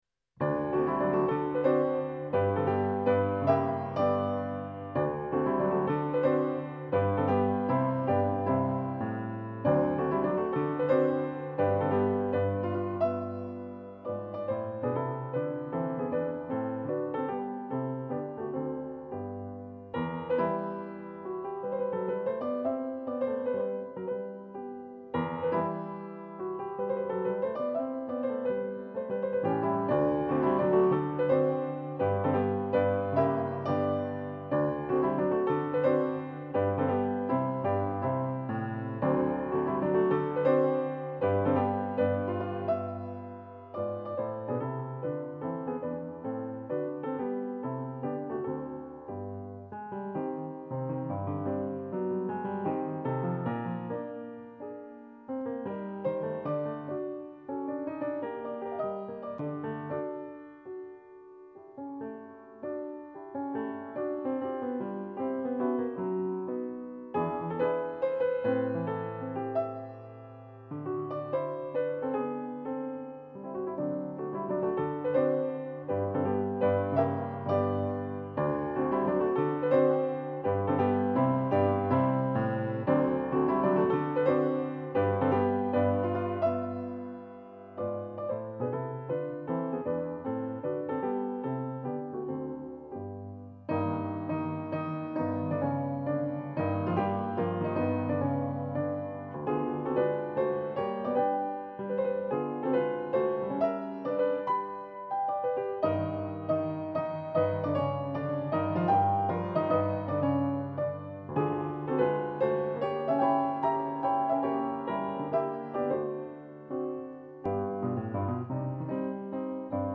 Piano version
Piano  (View more Advanced Piano Music)
Classical (View more Classical Piano Music)